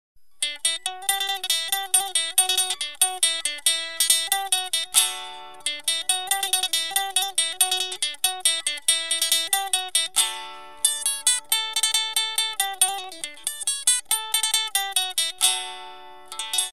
URM Sonos de Sardigna: nuovi strumenti - Banzucca elettrico